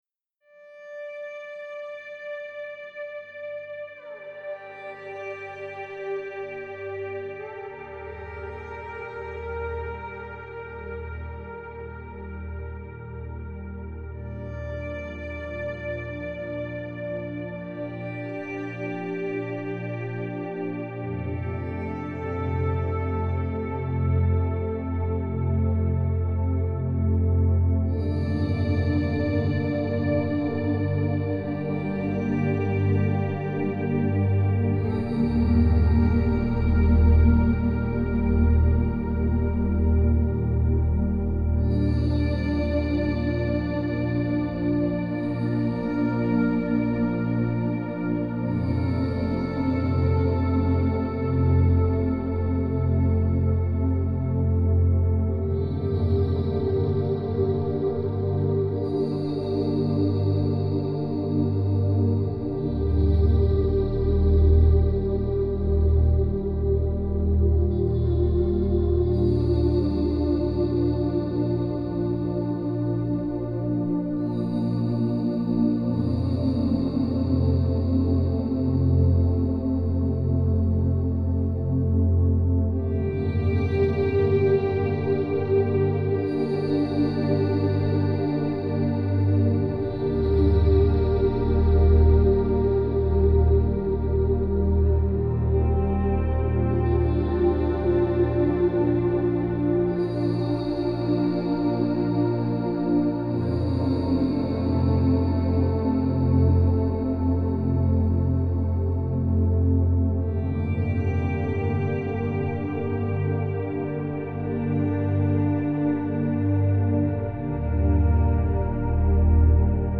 это мелодичная композиция в жанре инструментальной музыки